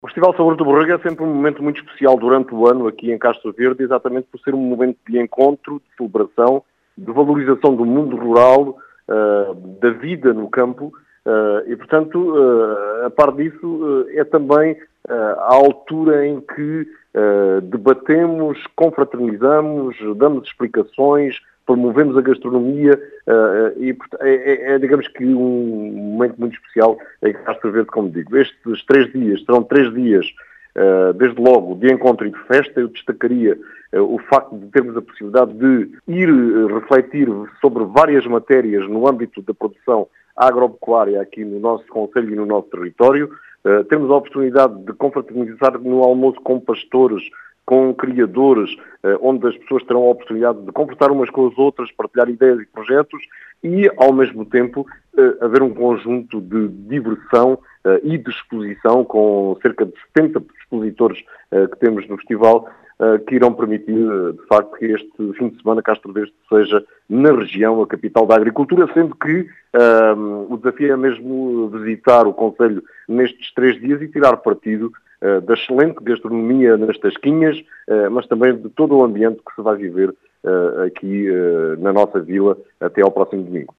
As explicações são do presidente da Câmara de Castro Verde, António José Brito, que fala de um “momento  muito especial”.